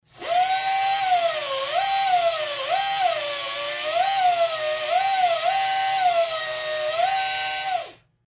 Звук чистки зубов бормашиной